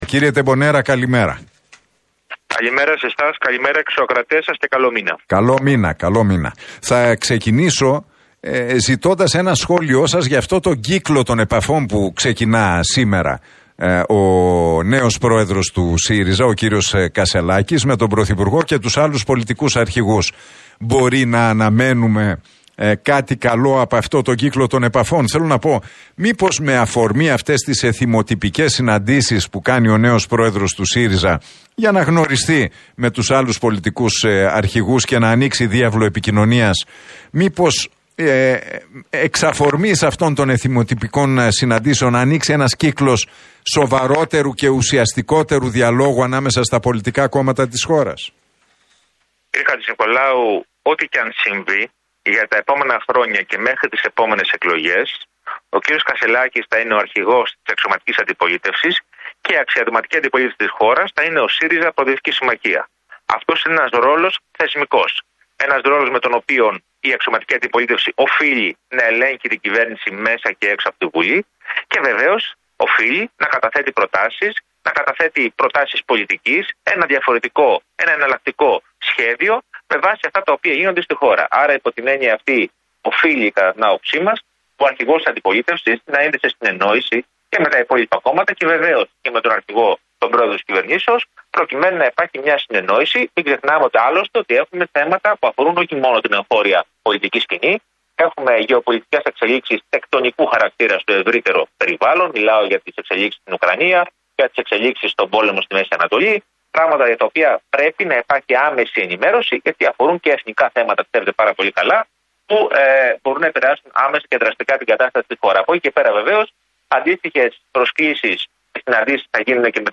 Τεμπονέρας στον Realfm 97,8: Δεν χρειαζόμαστε παιδονόμο στον ΣΥΡΙΖΑ, χρειαζόμαστε πολιτική ωριμότητα